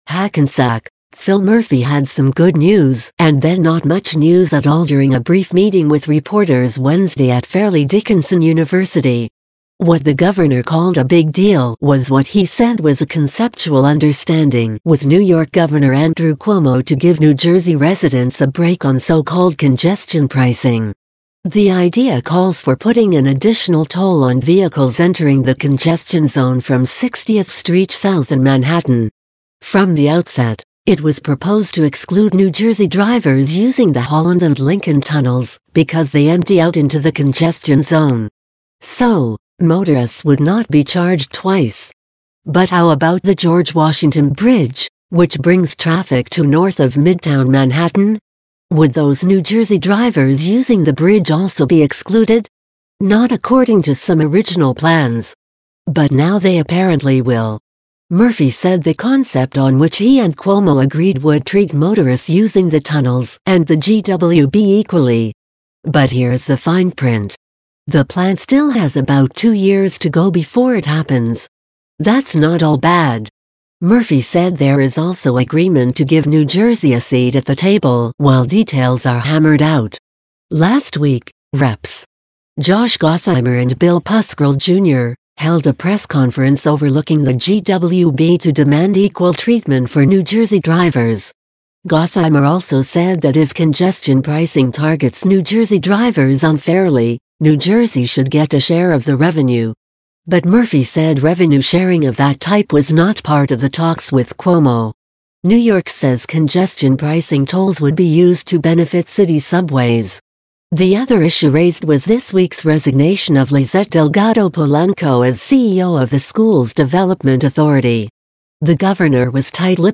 HACKENSACK – Phil Murphy had some good news and then not much news at all during a brief meeting with reporters Wednesday at Fairleigh Dickinson University.
Murphy, in fact, spoke from the head of a T-shaped table with reporters assembled about 100 feet away.